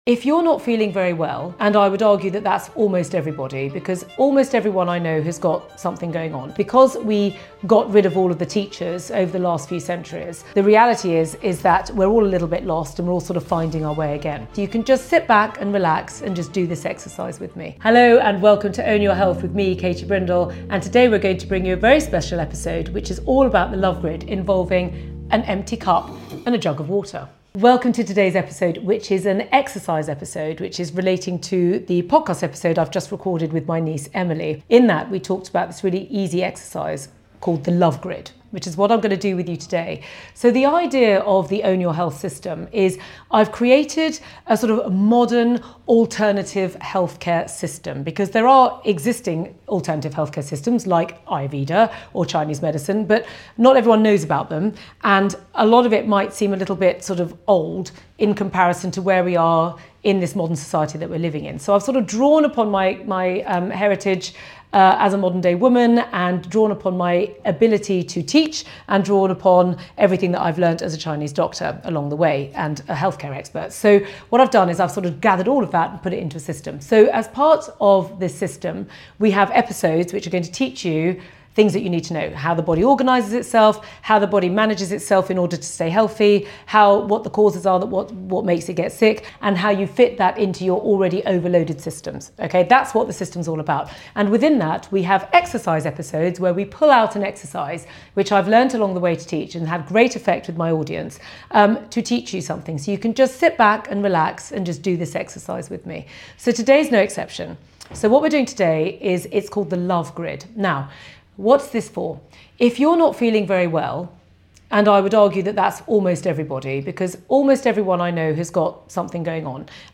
Guided Love Grid Meditation